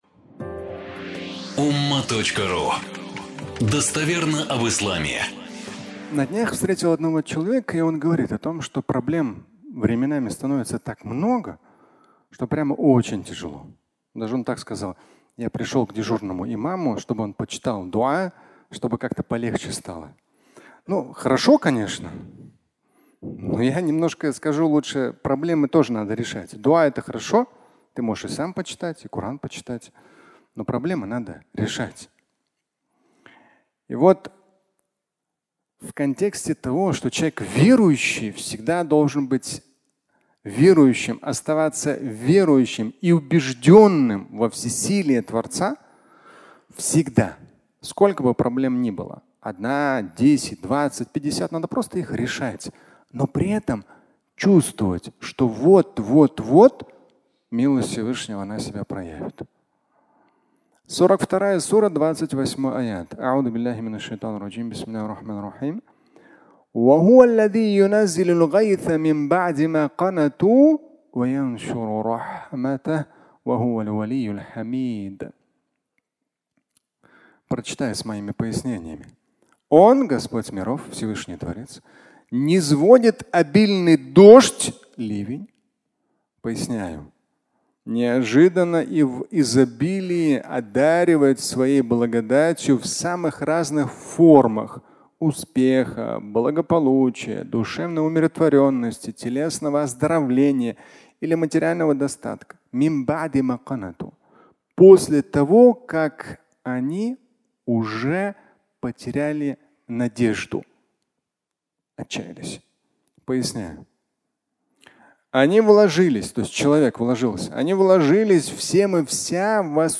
Устал от проблем (аудиолекция)